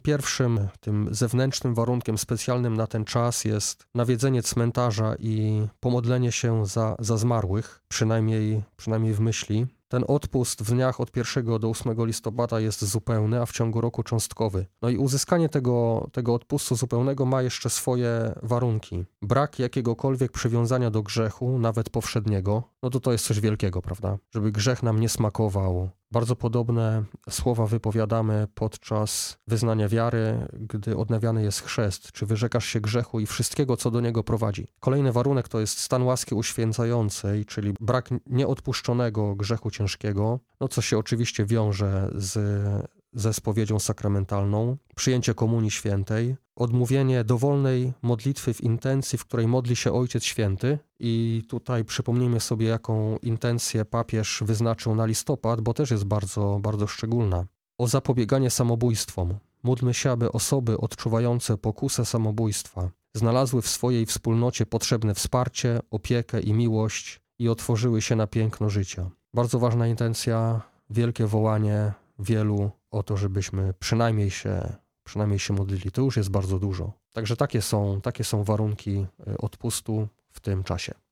W okresie od 1 do 8 listopada można uzyskać odpust zupełny za zmarłych. Aby go otrzymać należy spełnić kilka warunków, które przybliża biskup.